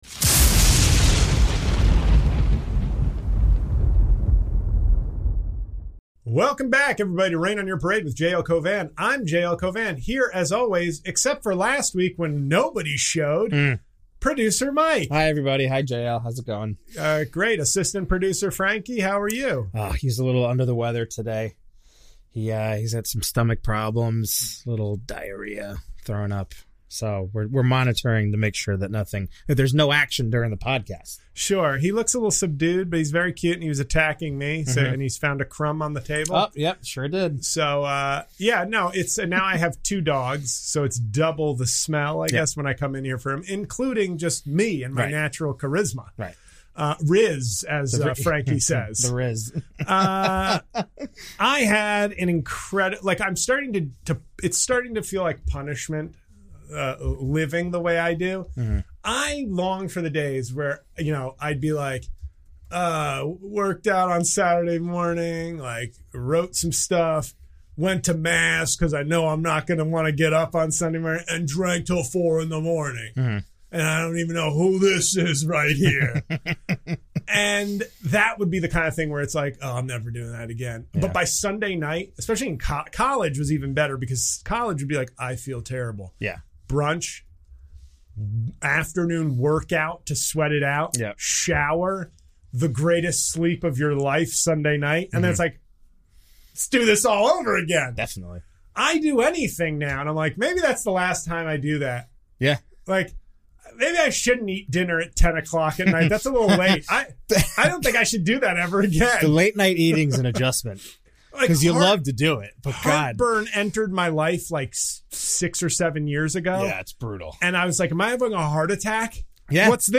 But does it give us the best Forest Whitaker impression?